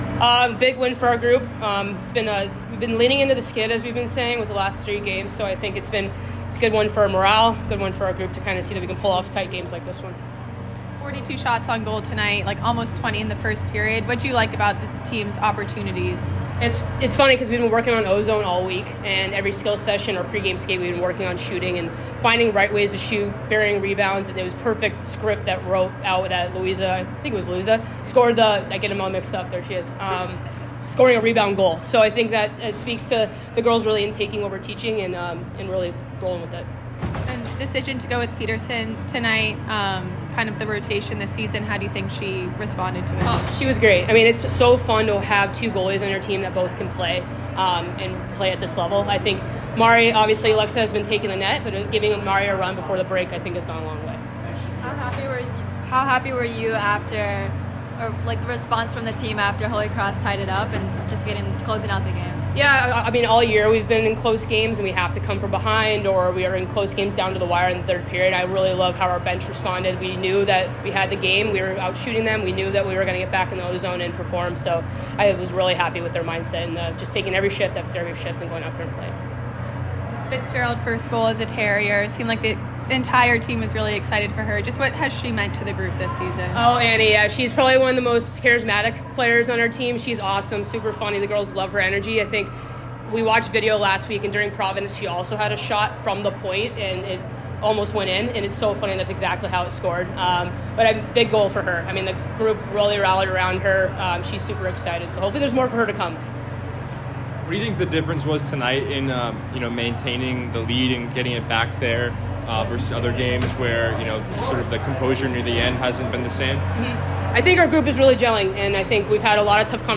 Holy Cross Postgame Interview